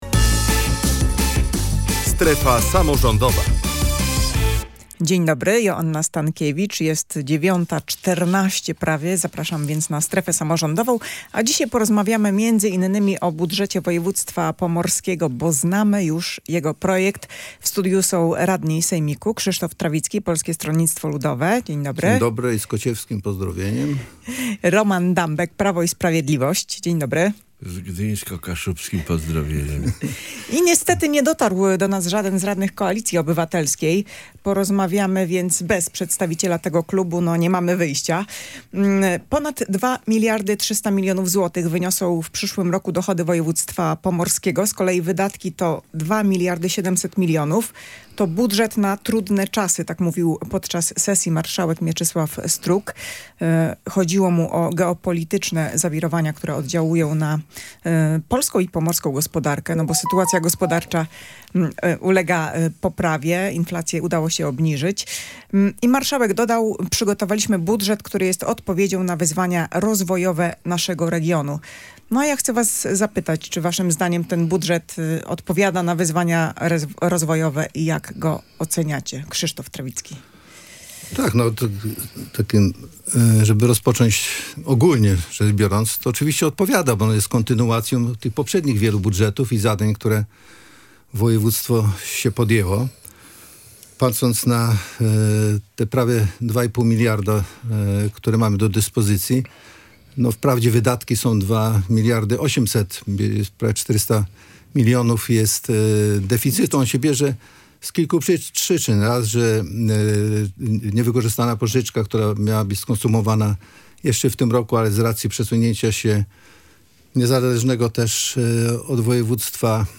Jej gośćmi byli radni sejmiku województwa: Krzysztof Trawicki z Polskiego Stronnictwa Ludowego oraz Roman Dambek z Prawa i Sprawiedliwości.